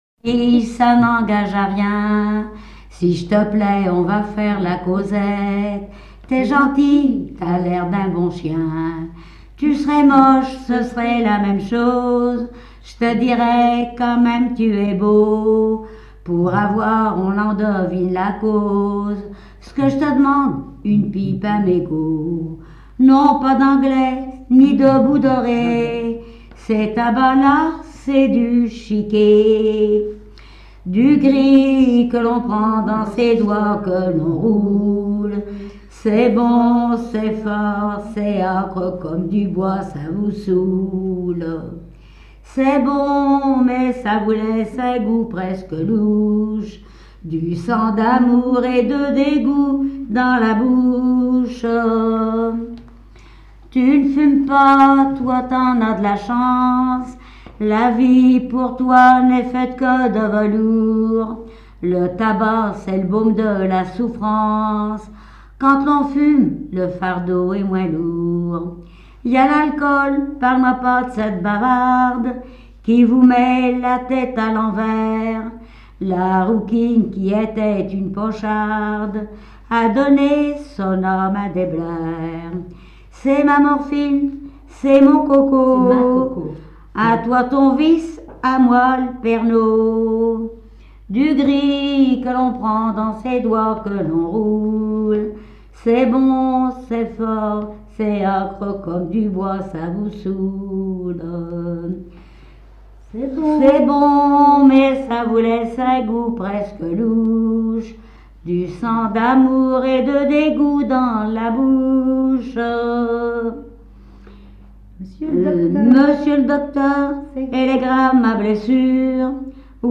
chanson au répertoire des usinières de conserverie
Genre strophique
témoignages sur le poissonnerie et chansons
Pièce musicale inédite